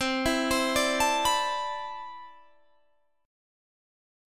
Listen to CM13 strummed